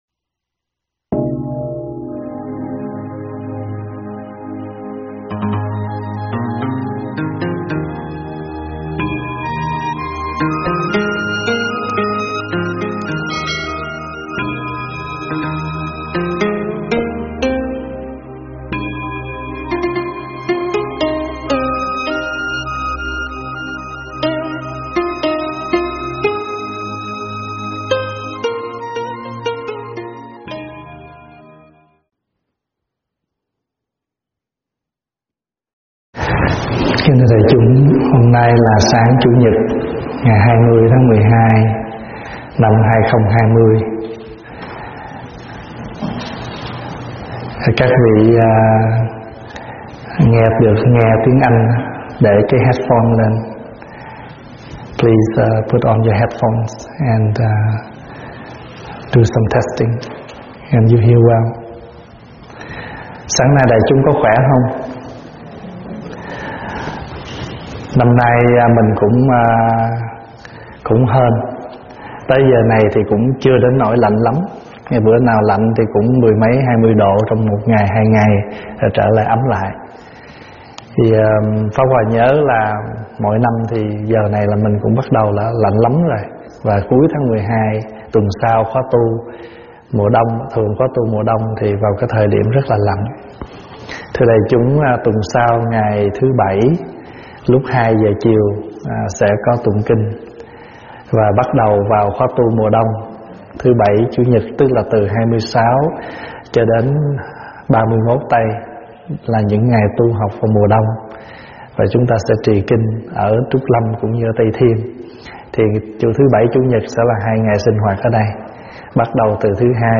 giảng tại Tv. Trúc Lâm